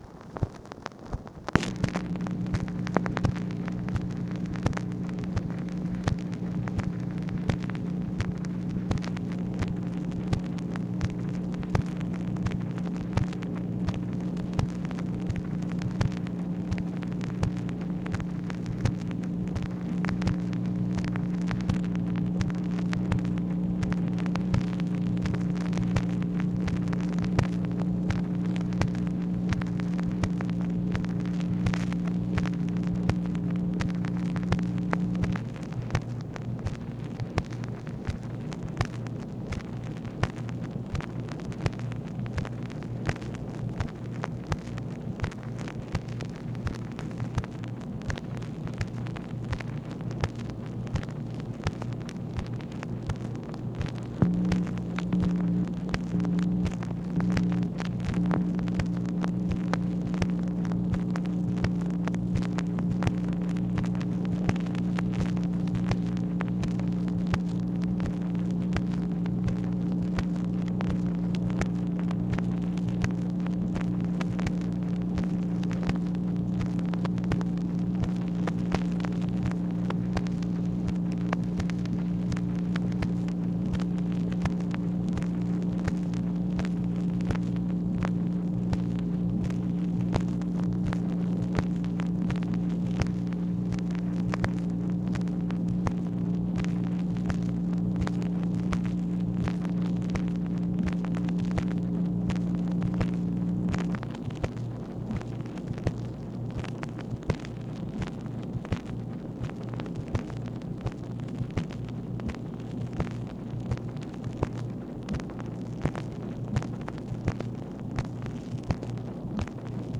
MACHINE NOISE, July 2, 1964
Secret White House Tapes